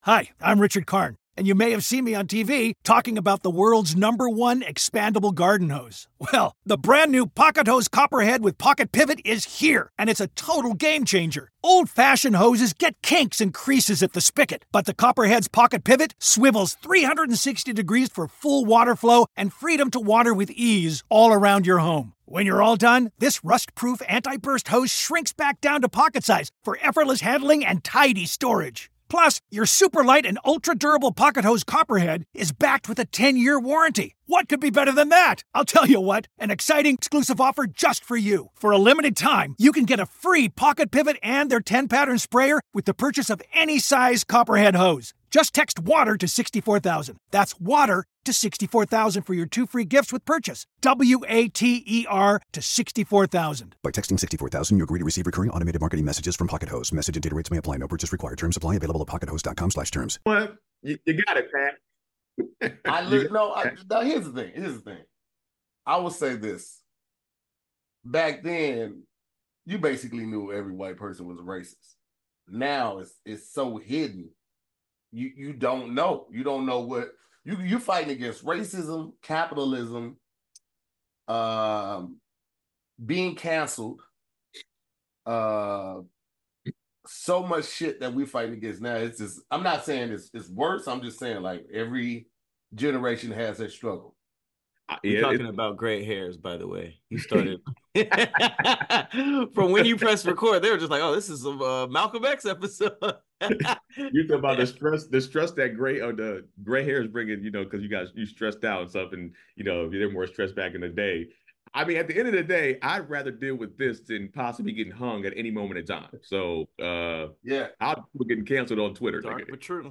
It’s a podcast with the OG SquADD! Each week, the SquADD will debate topics and vote at the end to see what wins.